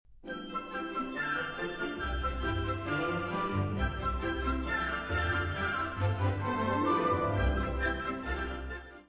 Īstā Mūzika MP3 formātā, kāda tā skan patiesībā